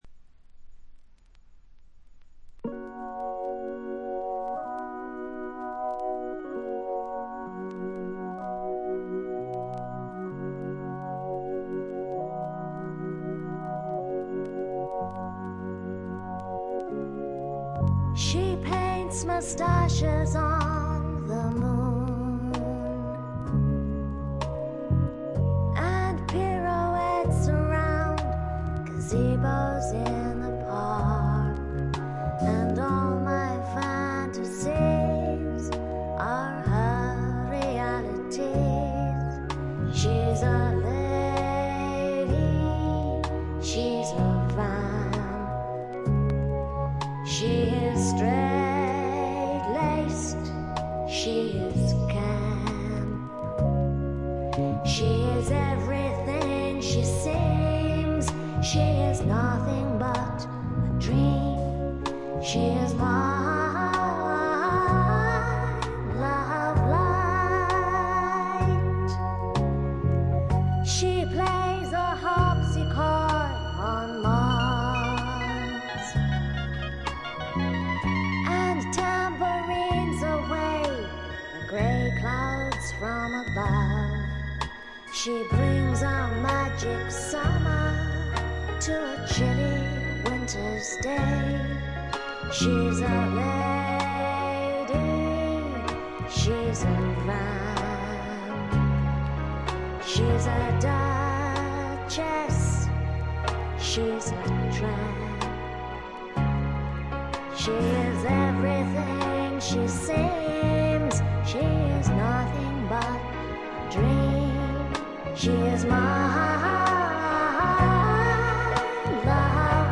静音部で微細なチリプチのみでほとんどノイズ感無し。
試聴曲は現品からの取り込み音源です。